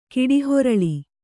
♪ kiḍihoraḷi